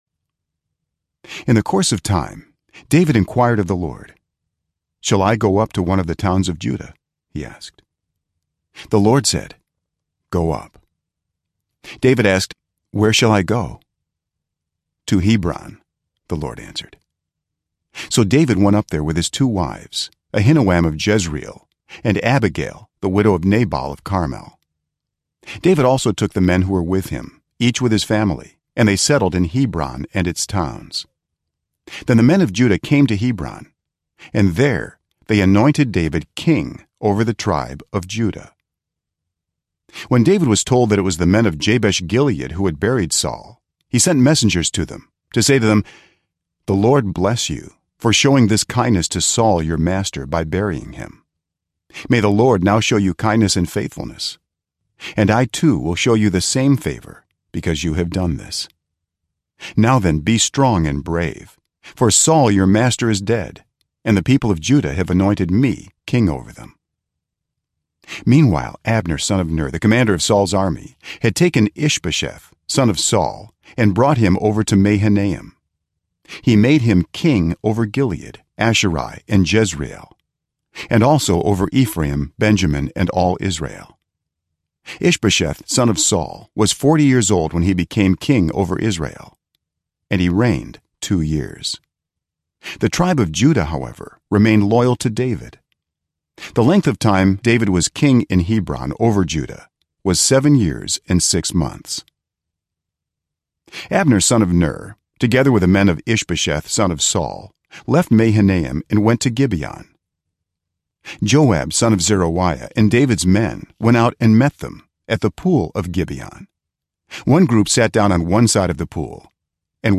Narrator
Pure Voice Bible NIV 2 Samuel ZV Sample.mp3